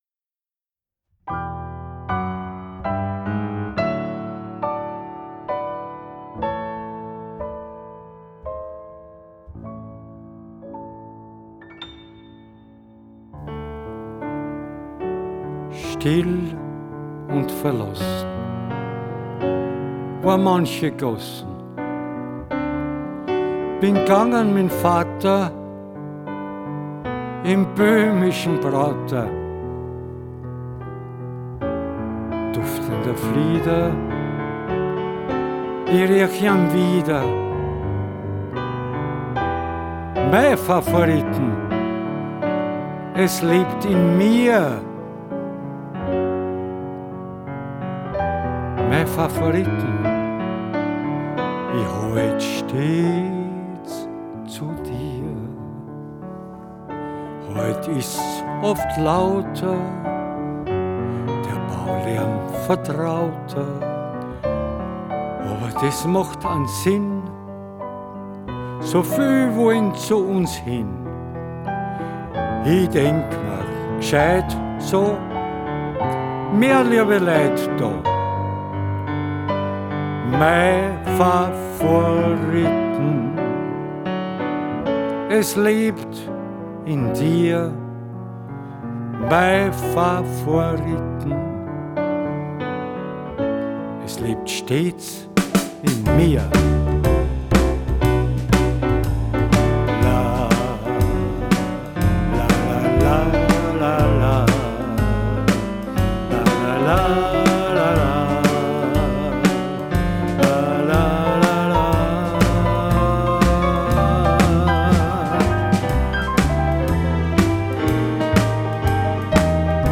Keyboard
Kontrabass
Schlagzeug